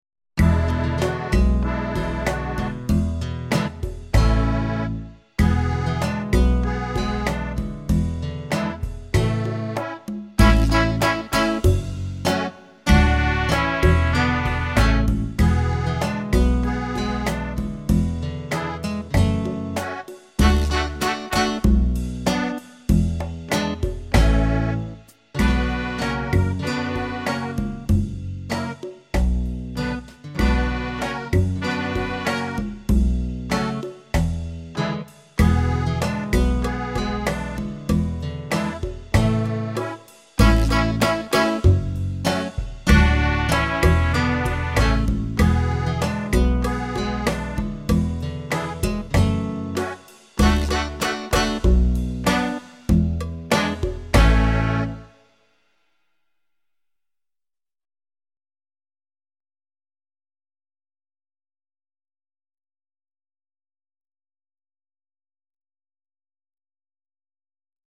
7. Havana Breeze (Backing Track)